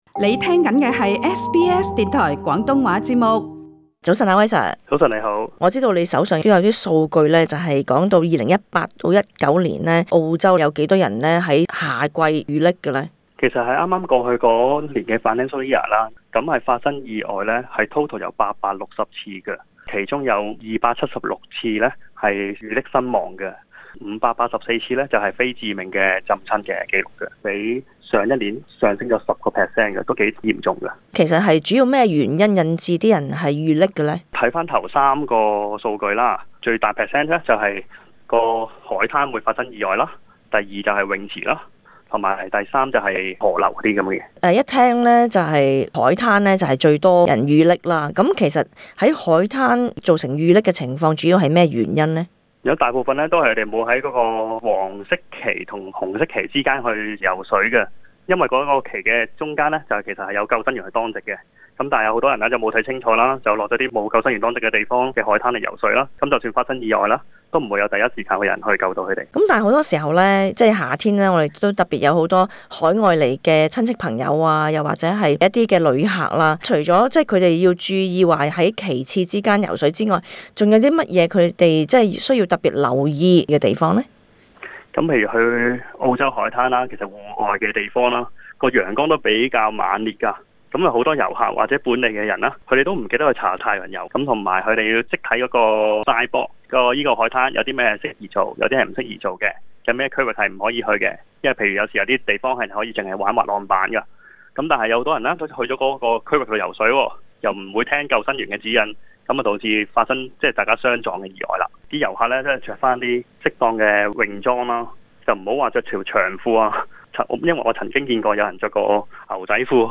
社區專訪